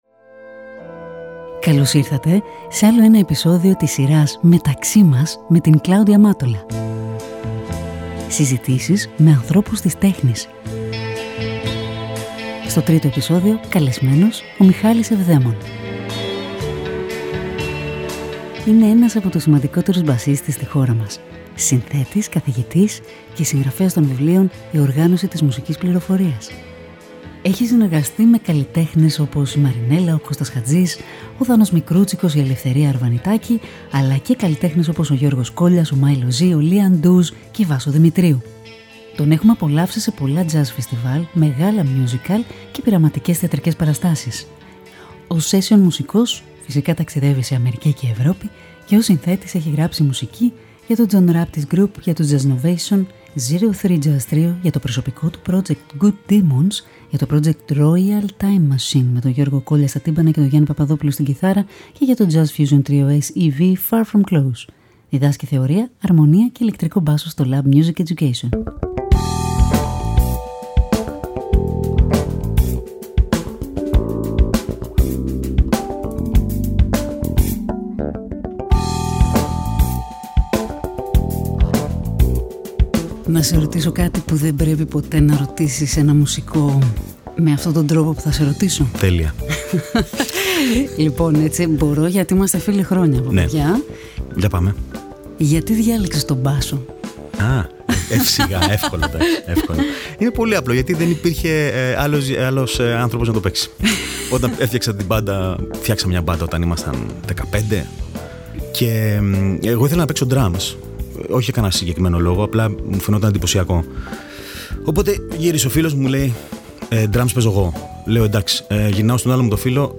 Μια οργανική συζήτηση με ανθρώπους της τέχνης.